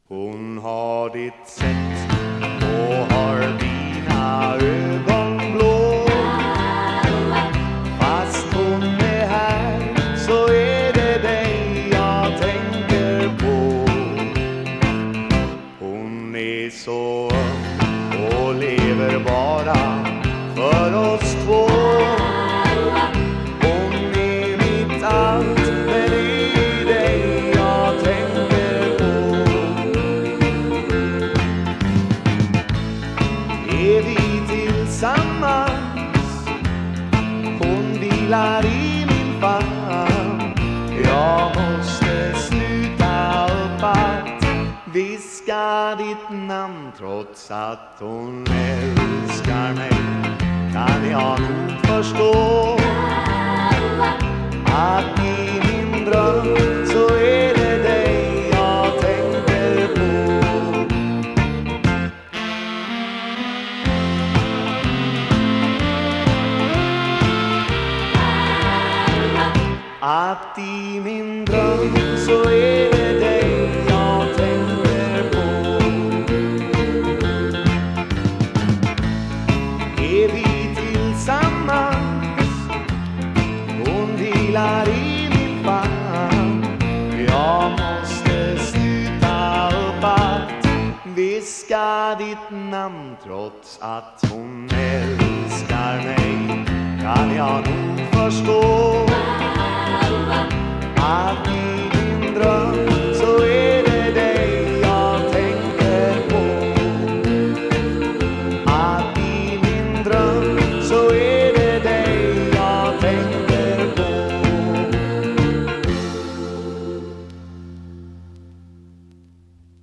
Vocals, Piano, Accordion
Bass
Vocals, Drums, Percussion
Vocals, Guitars
Sax